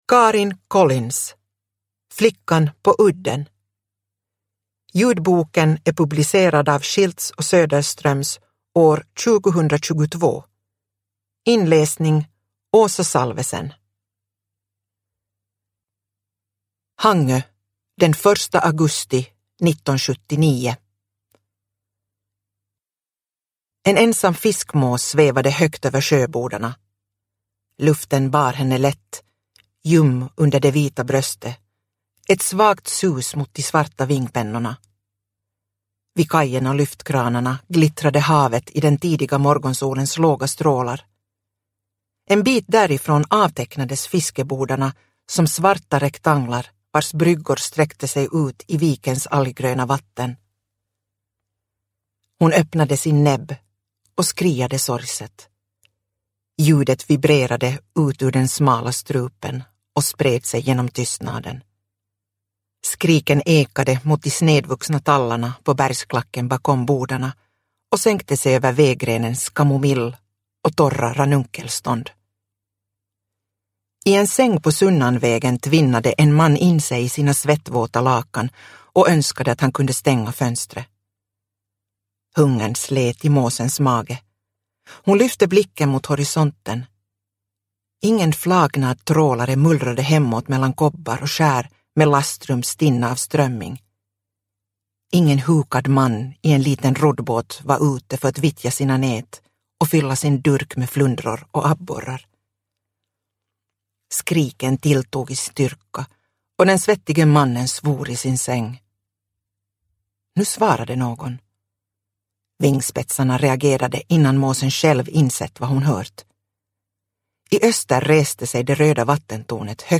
Flickan på udden – Ljudbok – Laddas ner